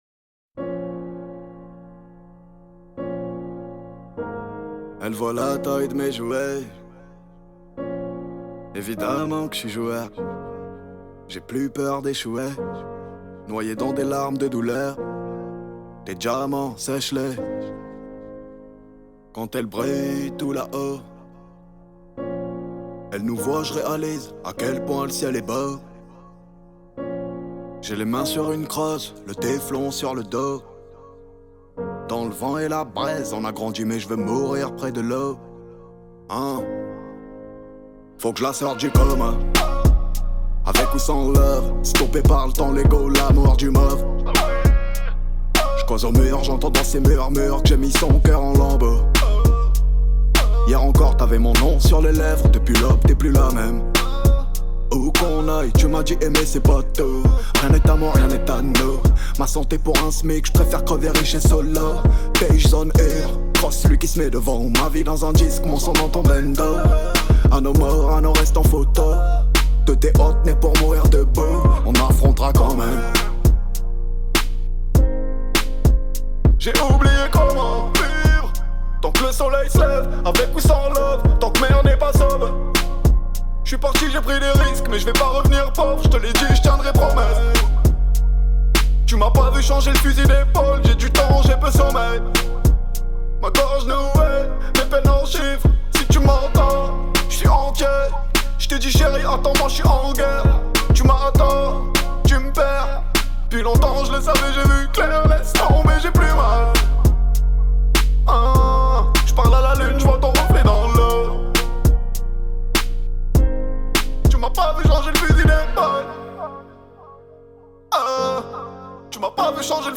59/100 Genres : french rap, pop urbaine Télécharger